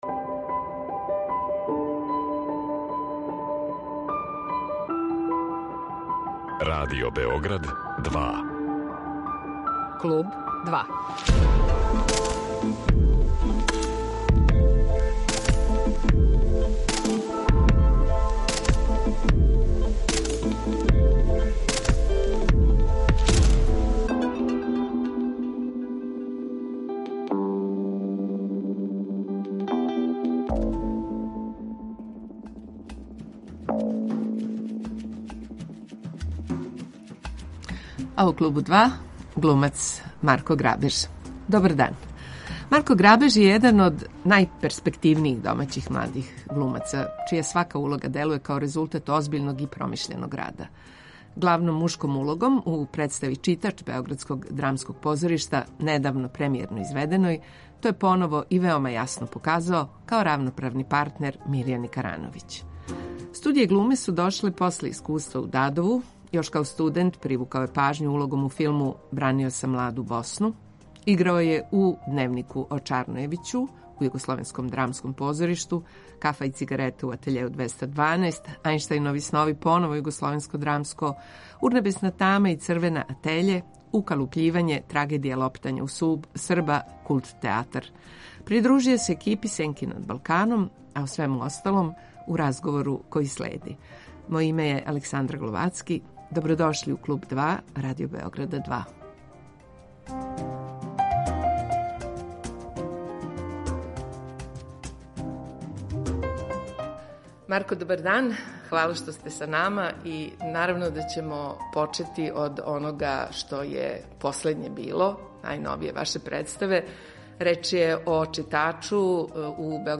Гост је Марко Грабеж